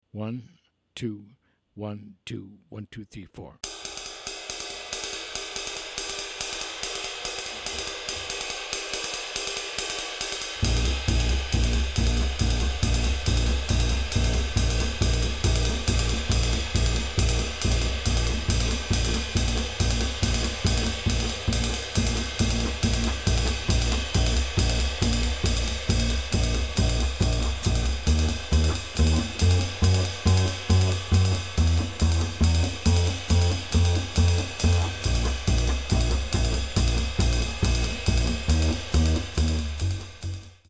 drum solo with piano comping and bass pedal 5 choruses
minus Piano